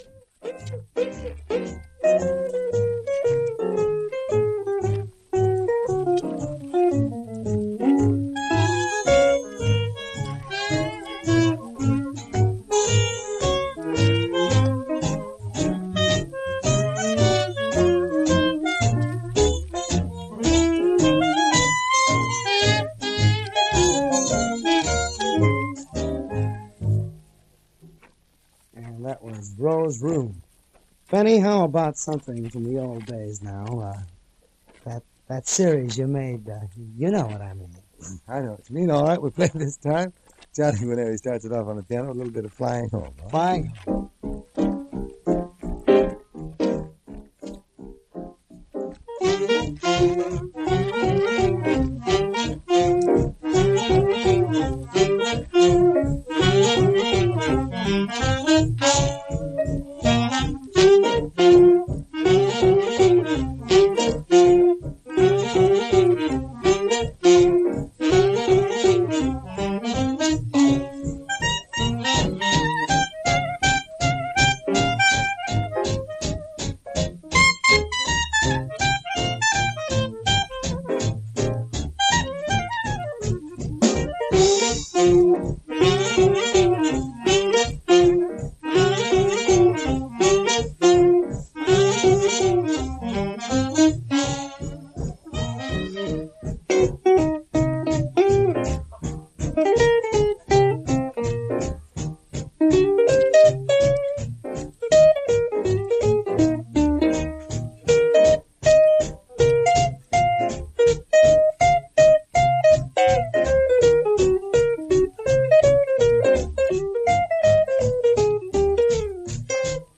small-combo Swing
piano
guitar
sax
trumpet
bass
drums